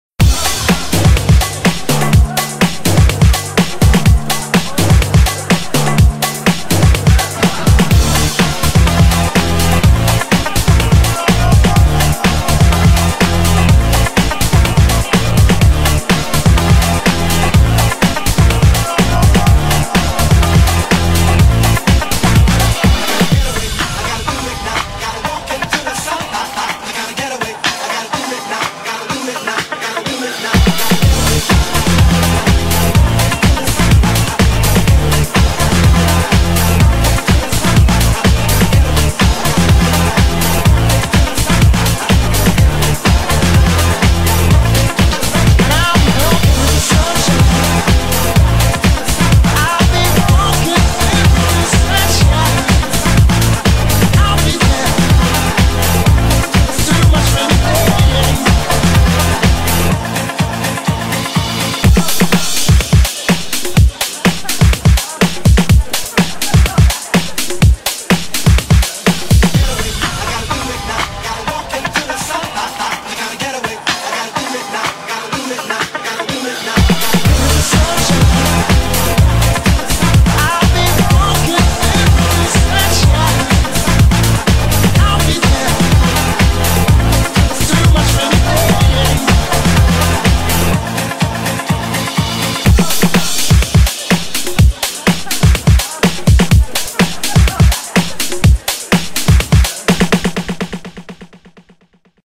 BPM125
Comments[NU-FUNK]